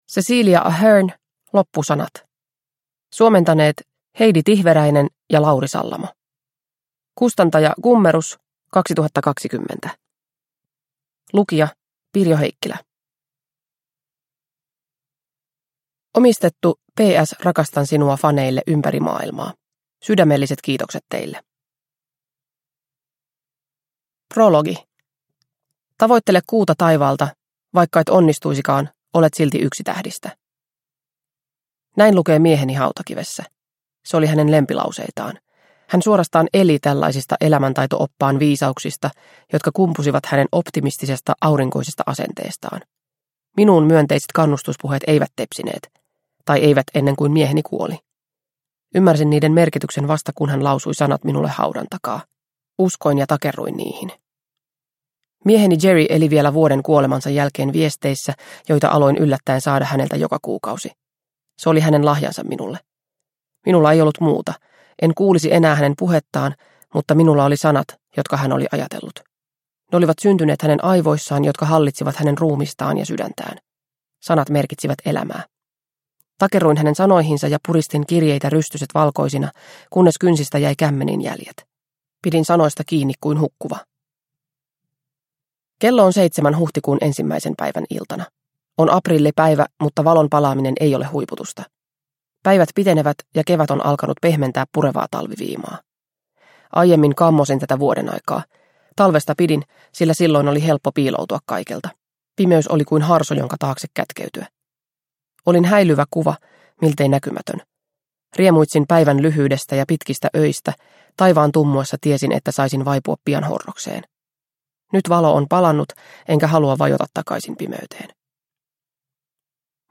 Loppusanat – Ljudbok – Laddas ner